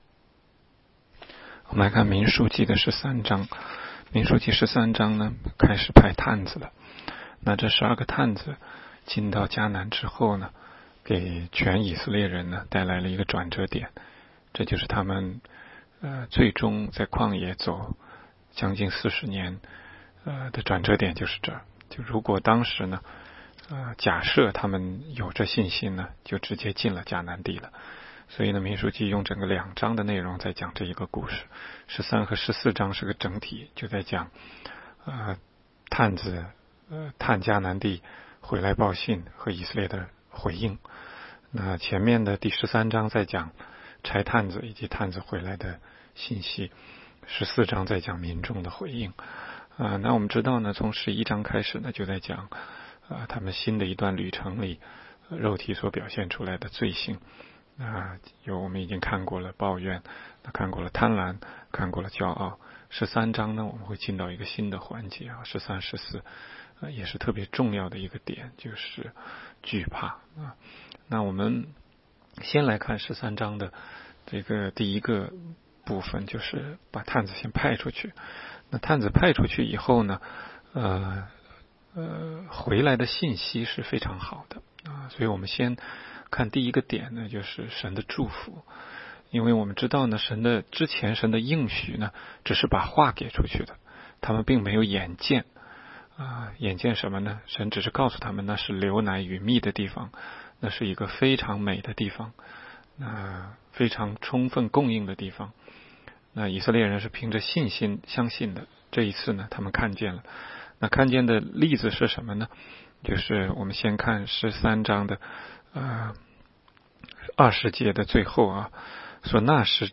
16街讲道录音 - 每日读经-《民数记》13章